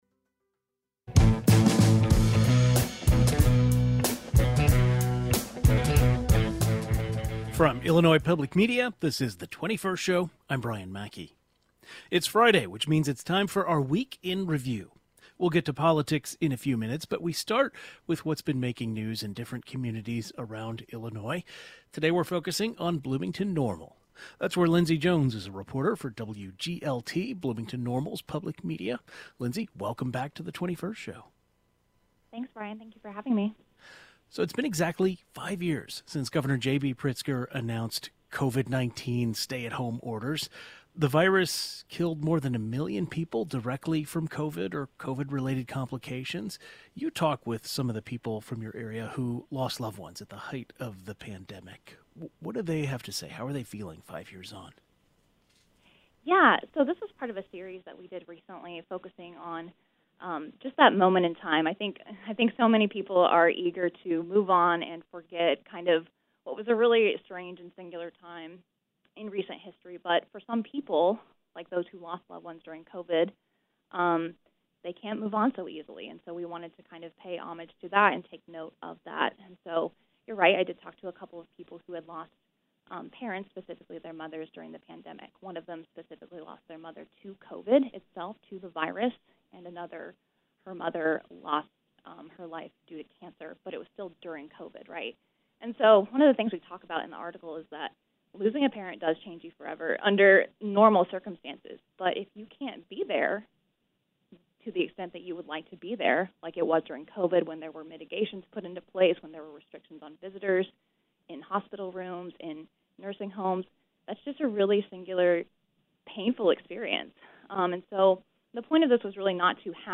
This week's Reporter Roundtable is focused on the Bloomington-Normal area.